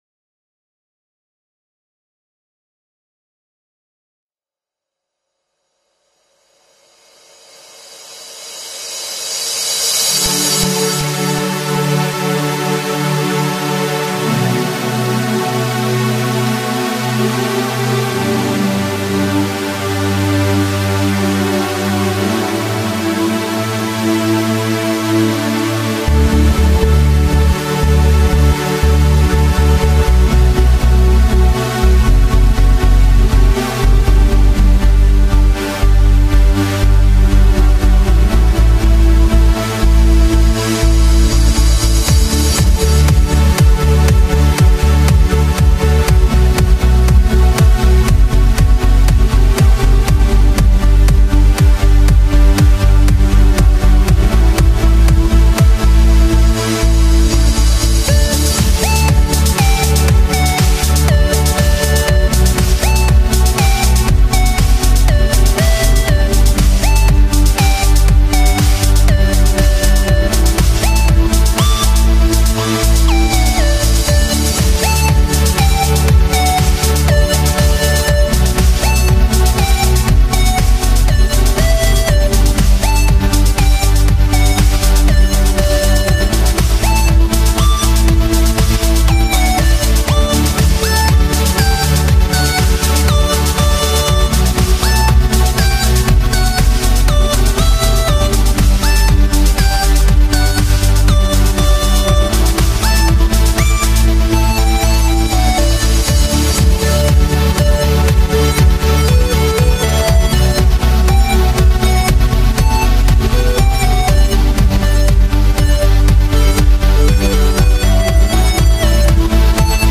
I decided to go full Trance Instead of progressive trance.